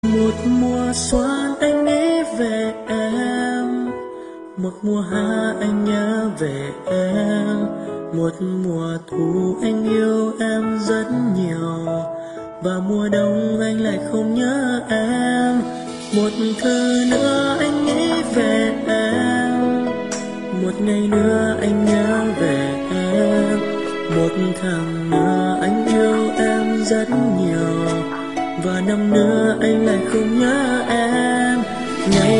Nhạc Trẻ